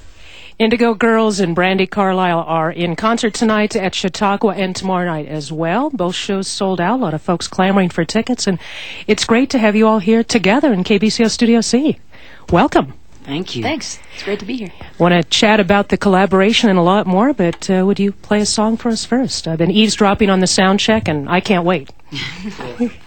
01. interview (0:23)